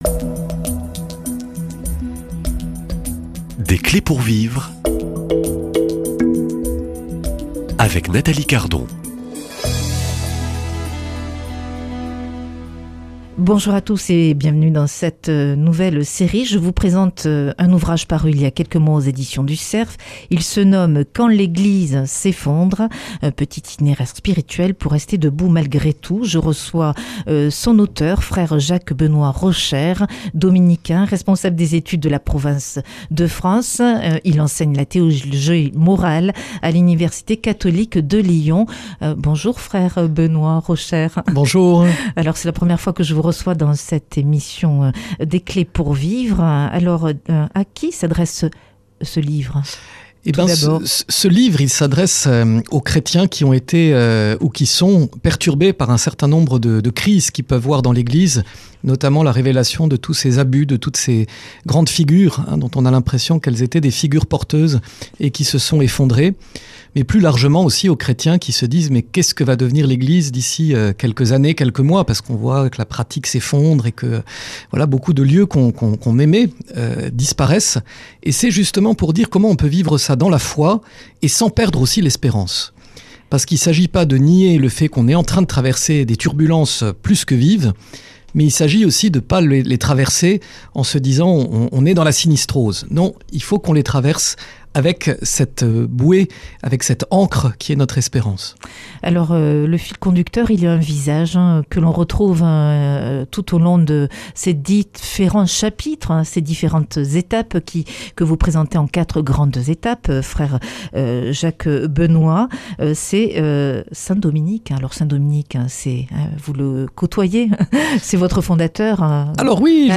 Quand l’Église s’effondre (Volet 1) Comment garder la foi et l’espérance ? Comment rester debout nous-mêmes, pierres vivantes de cet édifice ?Comment continuer le chemin ? Invité :Frère dominicain